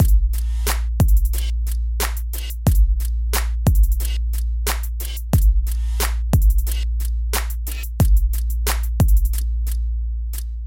旧学校 鼓循环13
Tag: 90 bpm Hip Hop Loops Drum Loops 1.79 MB wav Key : Unknown